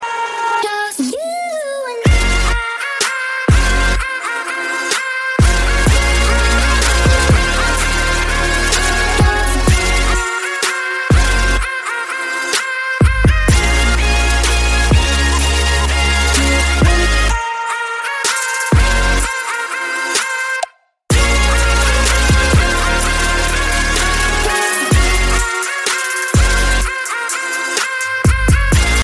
Рингтоны Без Слов
Рингтоны Ремиксы » # Рингтоны Электроника